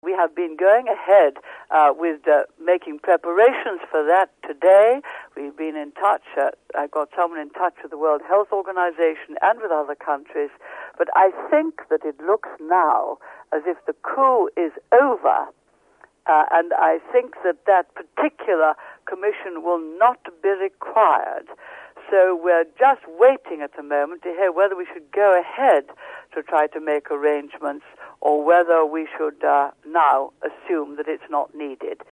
a rare telephone interview.